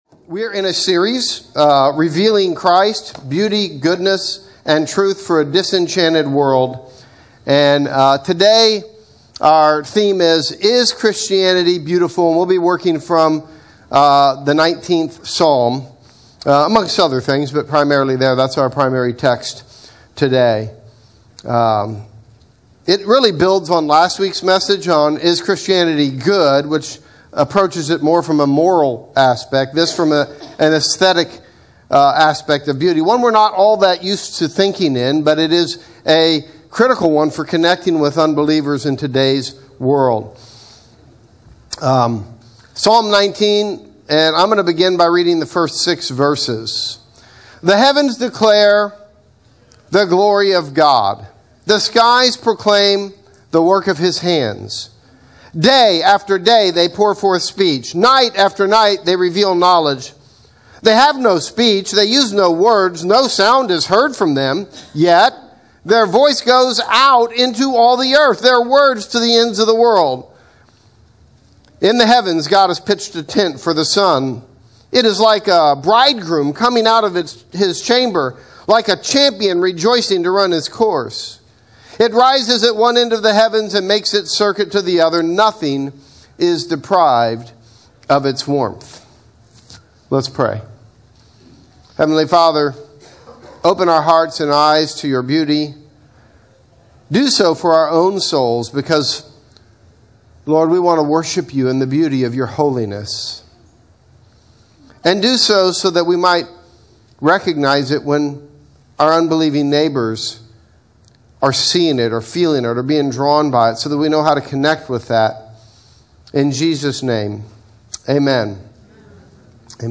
In this sermon, we explore what Scripture means when it speaks of God as glorious, radiant, and even sweet like honey. We reflect on the beauty of God’s character, the surprising beauty revealed in Jesus Christ, and the fragile, imperfect beauty of a church shaped by redemption rather than perfection.